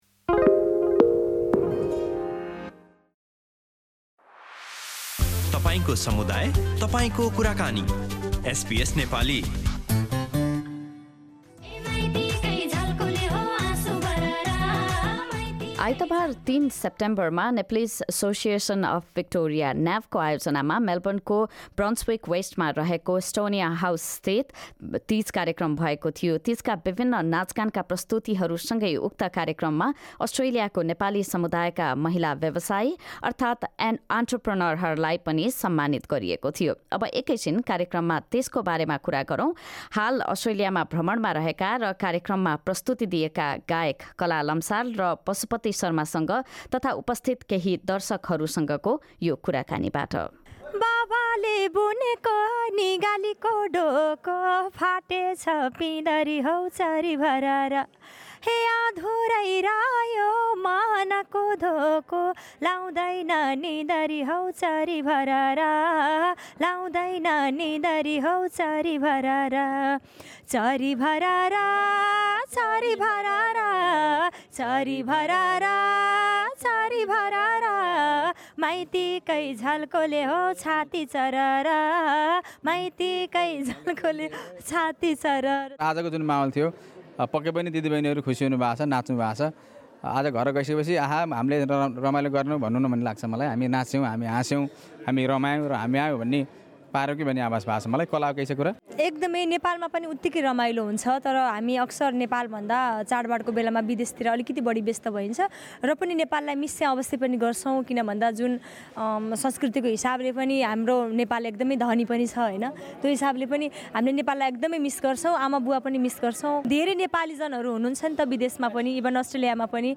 तीज आउन केही दिन मात्र रहँदा अस्ट्रेलियाको नेपाली समुदायमा विभिन्न कार्यक्रमहरू आयोजना हुन थालिसकेका छन्। यही अवसरमा भिक्टोरियाका नेपाली भाषी महिला उद्यमीहरूलाई सम्मानित गर्ने उद्देश्यका साथ नेप्लीज एसोसिएसन अफ भिक्टोरिया (न्याभ)ले आइतवार, १३ सेप्टेम्बरमा मेलबर्नस्थित एस्टोनियन हाउसमा एक कार्यक्रम आयोजना गरेको थियो। कार्यक्रमका सहभागीहरूसँग गरिएको कुराकानी सहितको रिपोर्ट सुन्नुहोस्।